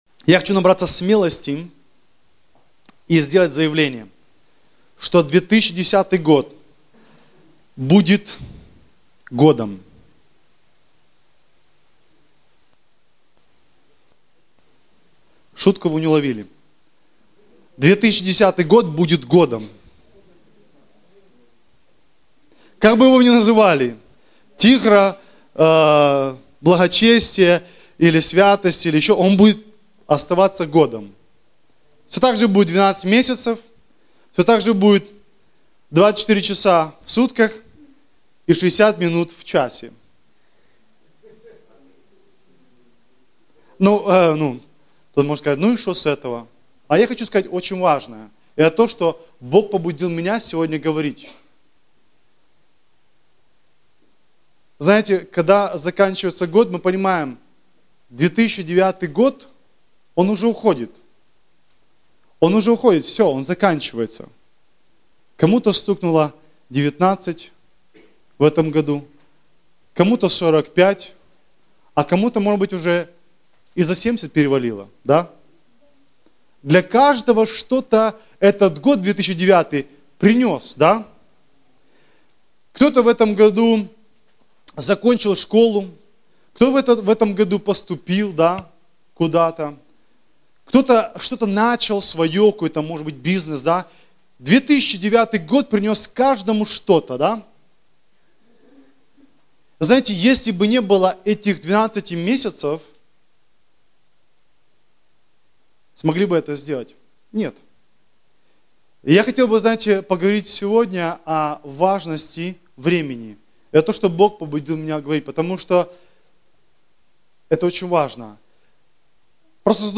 Актуальная проповедь